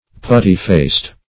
Putty-faced \Put"ty-faced`\, a.
putty-faced.mp3